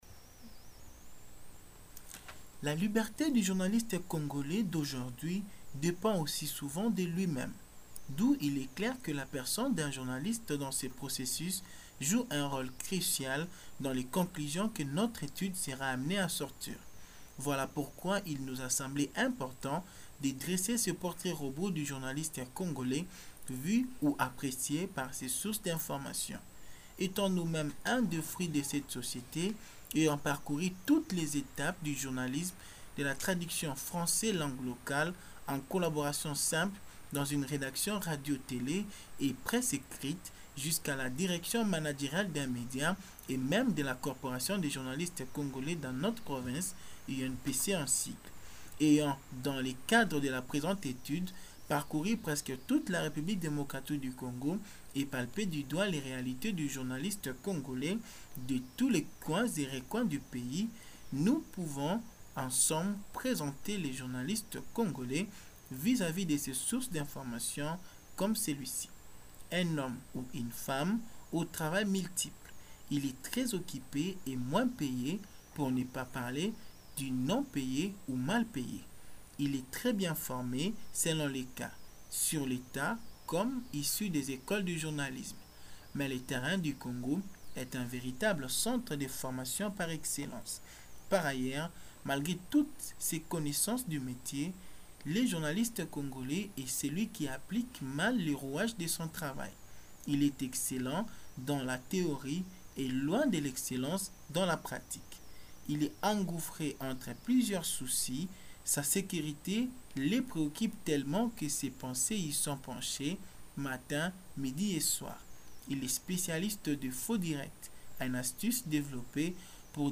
Témoignages de journalistes